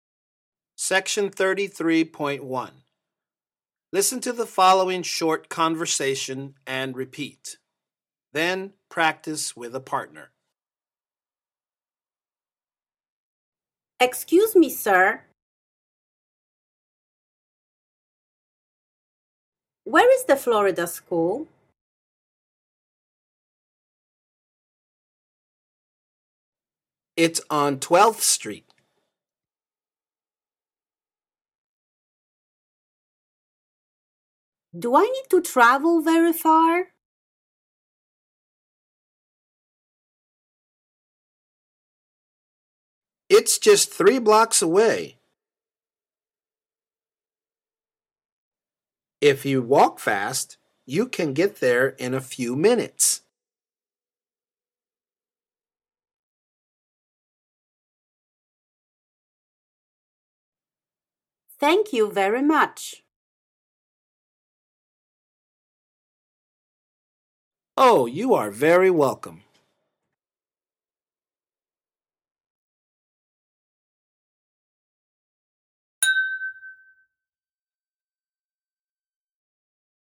Here is an audio sample for the “soon to be released” Level 1 ESL Textbook “I Want To Learn English.” It comes from Unit 3 which focuses on consonant blends.